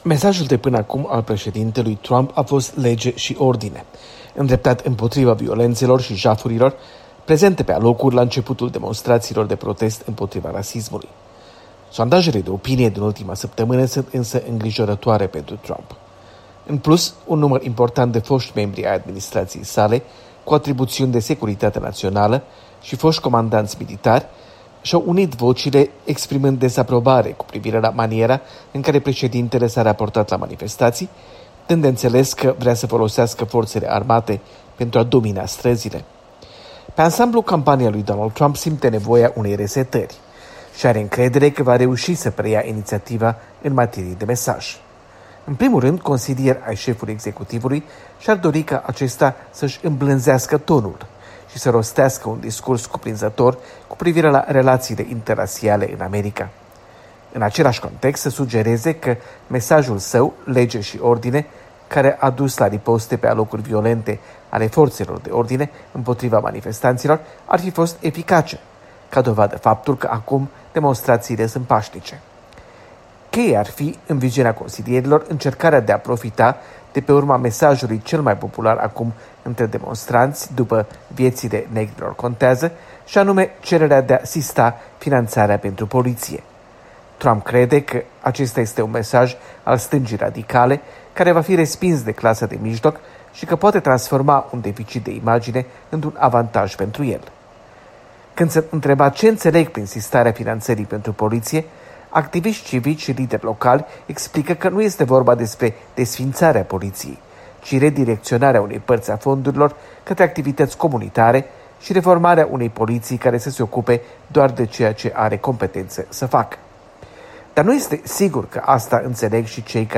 Corespondență de la Washington: a 12-a zi de proteste